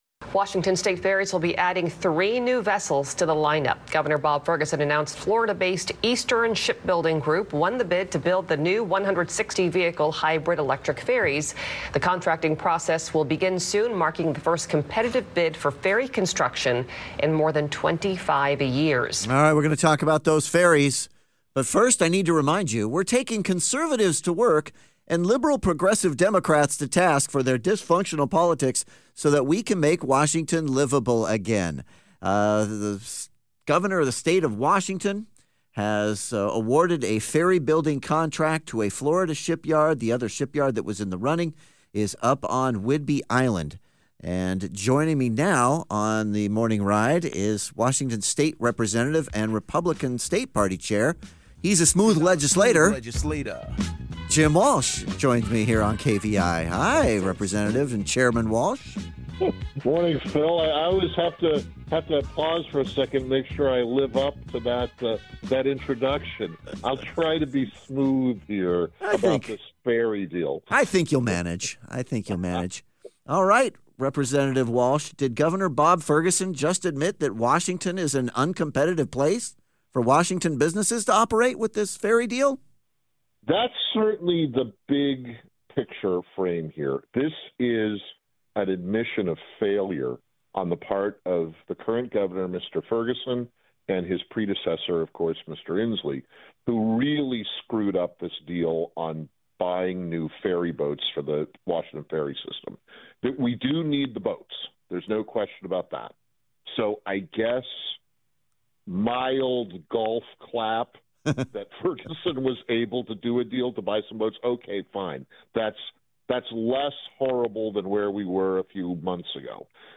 WAGOP Chairman and State Representative Jim Walsh joins The Morning Ride to discuss how Governor Bob Ferguson recently selected a Florida based ship builder to construct 3 new ferries for the state saving money over the bid made by a locally based company here in Washington.